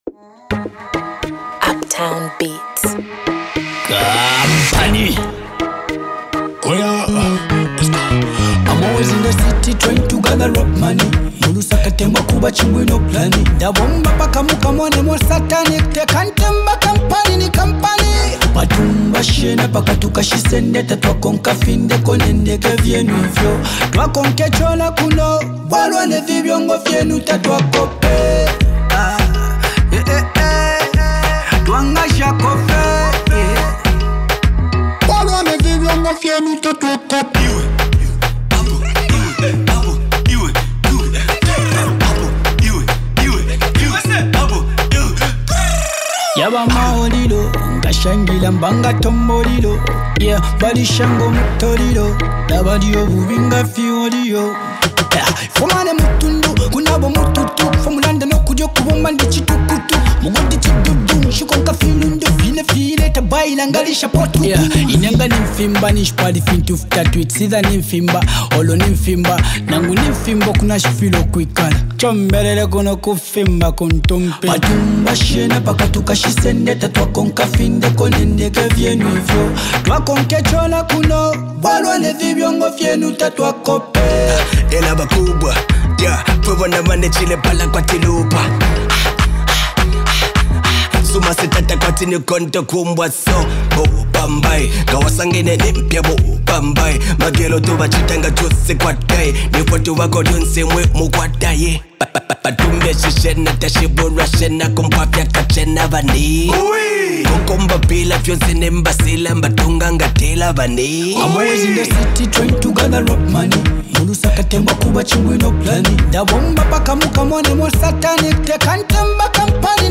electrifying track
soulful vocals
melodic touch adds a layer of warmth and polish
Blending Afrobeat, dancehall, and Zambian urban influences